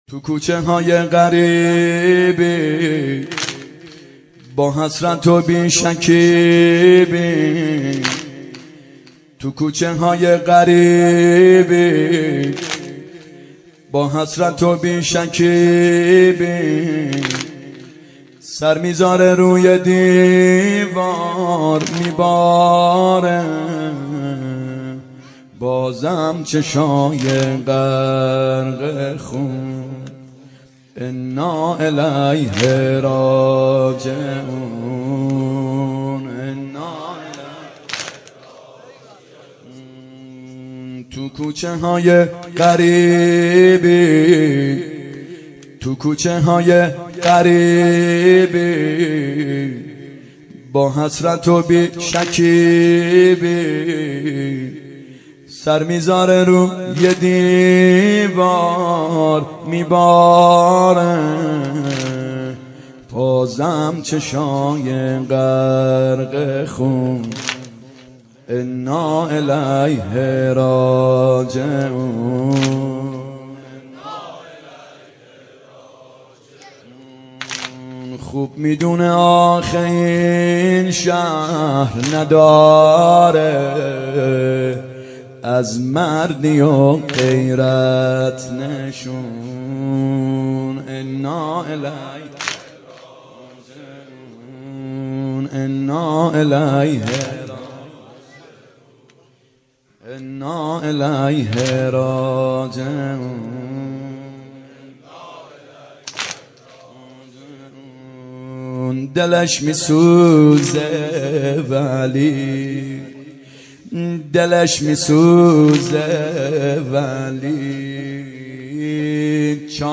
محرم 91 واحد شب اول (توکوچه های غریبی
محرم 91 ( هیأت یامهدی عج)